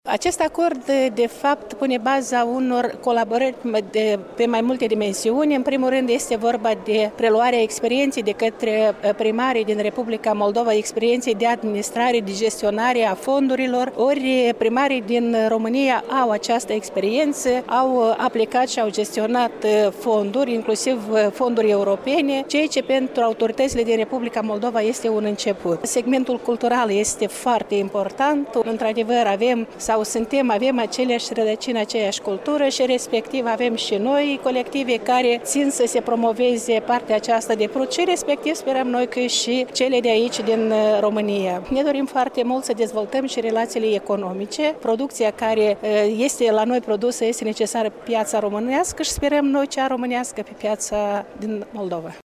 Preşedintele Consiliului Raional Ungheni, Ludmila Guzun a declarat că primarii din stânga Prutului vor prelua de la omologii lor din România experienţa în domeniul administrării proiectelor şi a fondurilor europene şi un rol deosebit în cadrul colaborărilor îl va avea schimbul de experienţă în domeniul agriculturii.